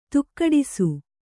♪ tukkaḍisu